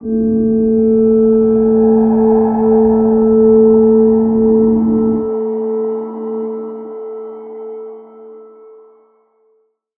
G_Crystal-A4-f.wav